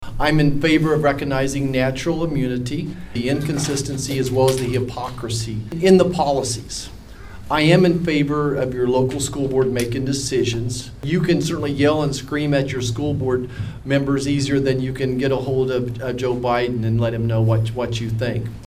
Senator Roger Marshall spoke to members of the community during his town hall over the weekend.